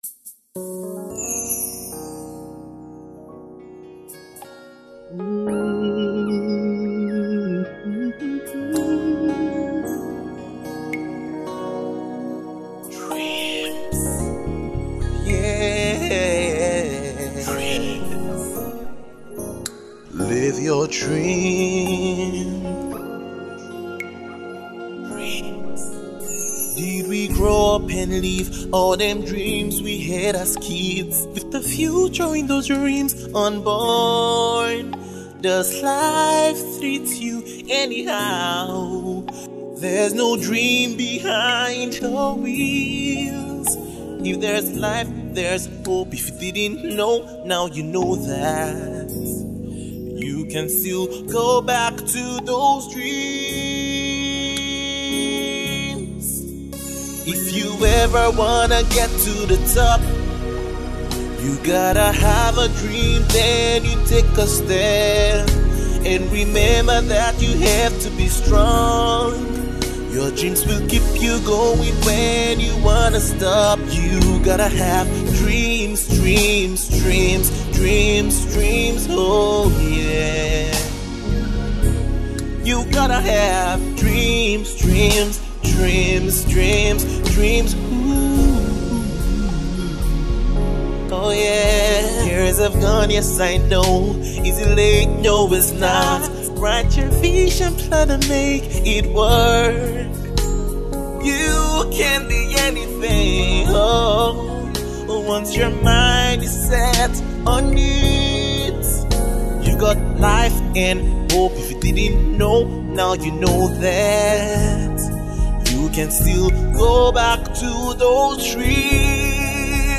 gospel R&B/Soul